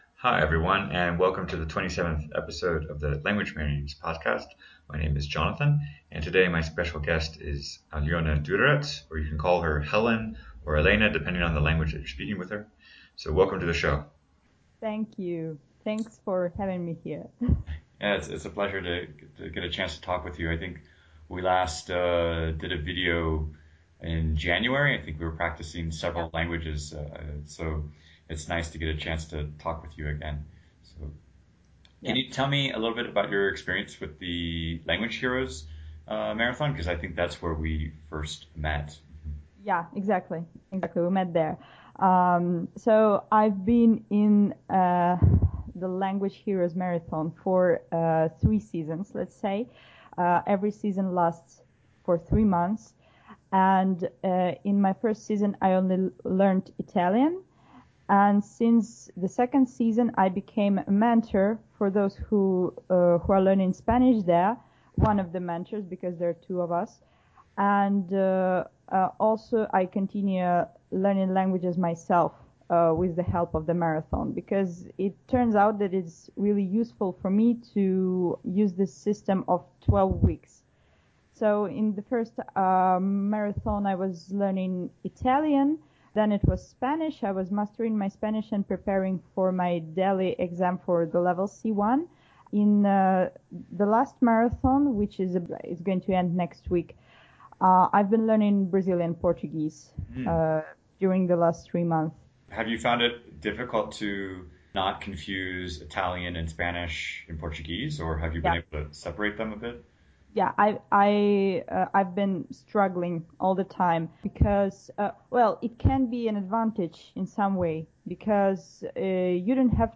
Episode 27 - Interview